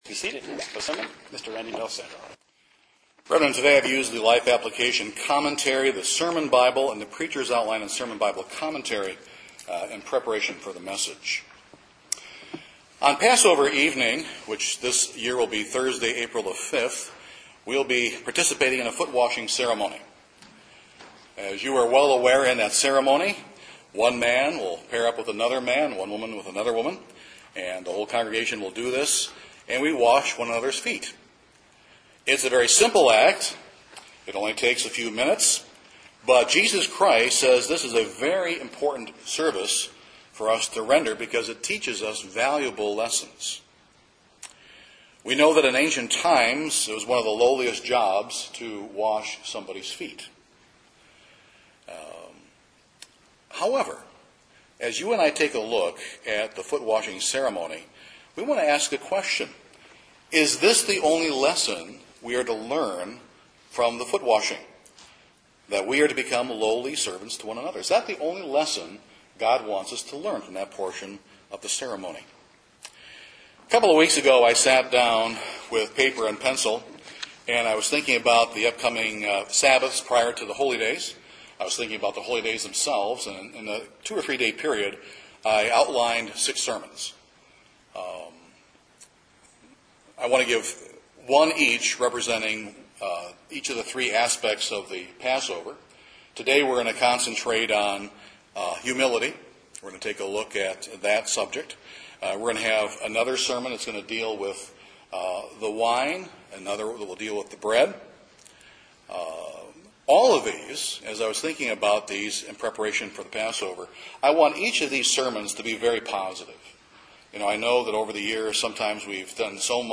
This sermon will reveal four dynamic ways that humility brings healing to the Christian.